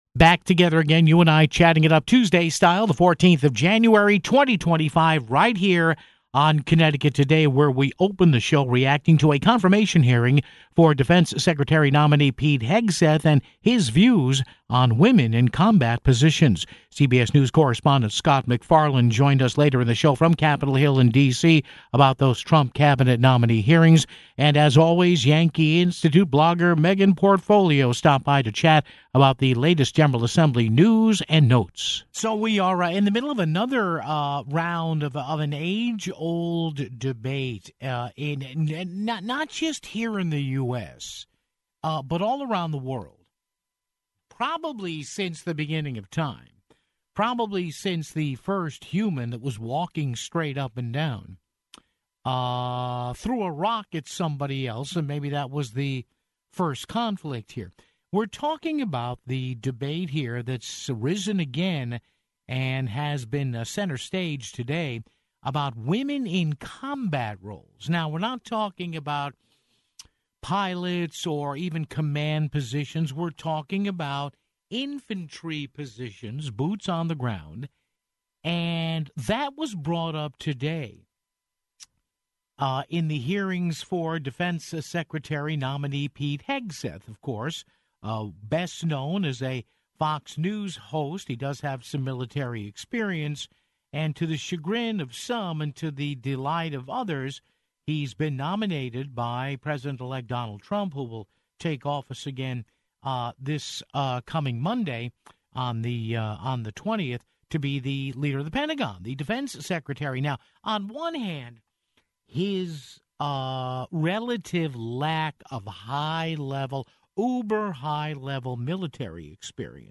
CBS News correspondent Scott MacFarlane joined us from Capitol Hill in D.C. on those Trump Cabinet nominee hearings (14:58).